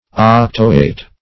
octoate - definition of octoate - synonyms, pronunciation, spelling from Free Dictionary Search Result for " octoate" : The Collaborative International Dictionary of English v.0.48: Octoate \Oc"to*ate\, n. (Chem.) A salt of an octoic acid; a caprylate.